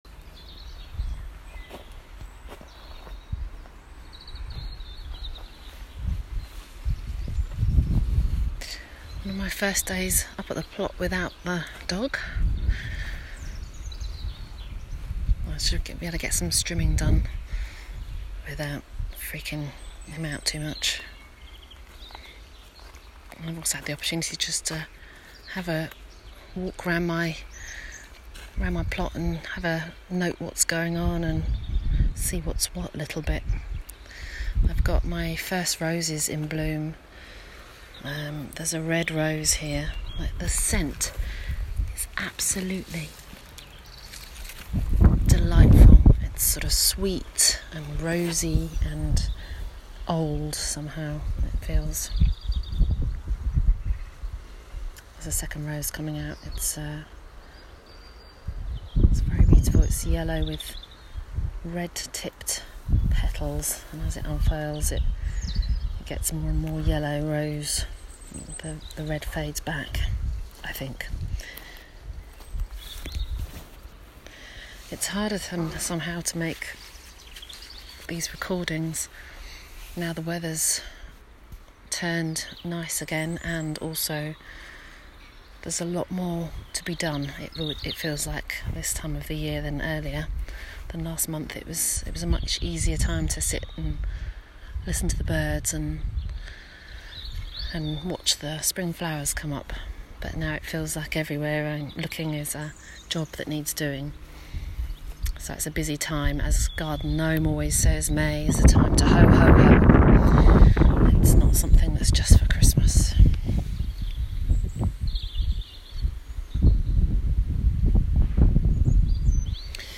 Hear from one woman and her dog as she explores the organic gardening world in short sound bites.
*Please note, outside recording carried out in accordance with national guidance as part of daily exercise with respect to social distancing*